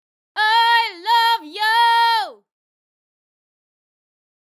Its character is often direct, loud, and shouted, like when you call ‘hey’ after someone in the street.
#111 (Female)
‘OO’, ‘O’, ‘OR’, and ‘AH’ are altered to ‘OH’.